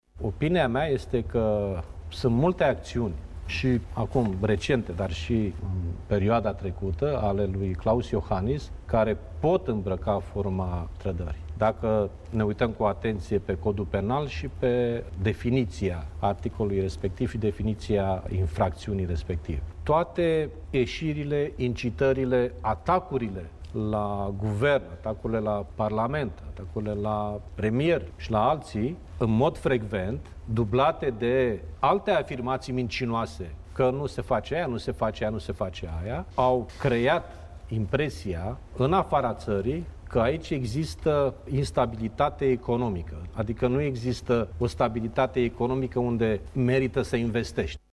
Liviu Dragnea a explicat că documentul a fost pregătit de colegi din partid şi că ştia că se lucrează la elaborarea lui: